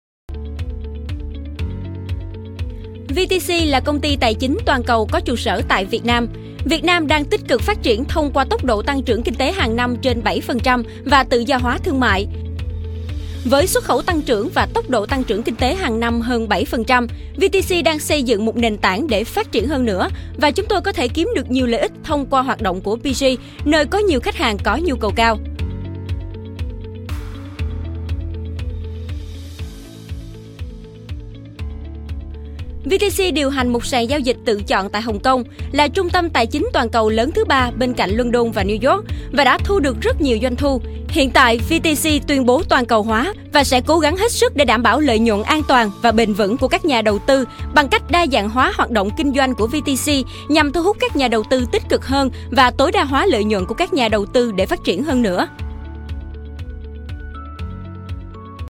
Demos of Vietnamese female voices
Female voice over for corporate video 1
Vietnamese-female-voice-corporate-video-upbeat.mp3